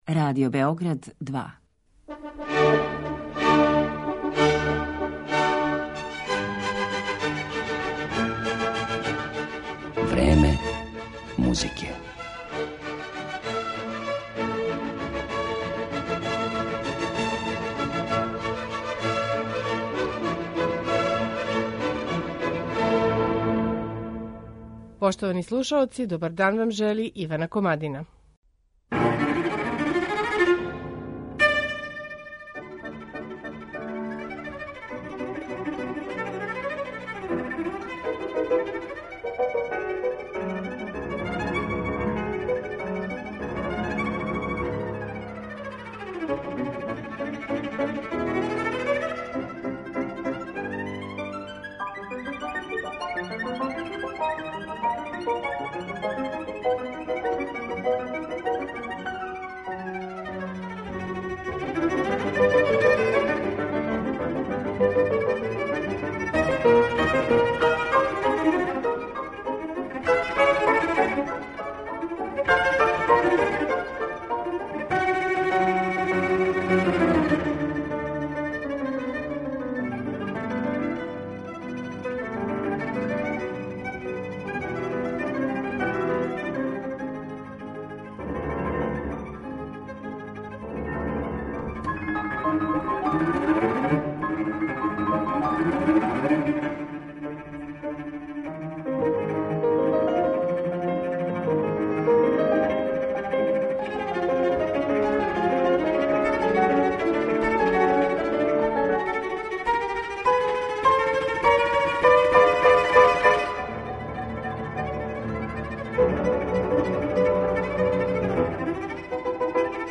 Виолончелиста Албан Герхард